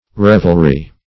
Revelry \Rev"el*ry\, n. [See Revel, v. i. & n.]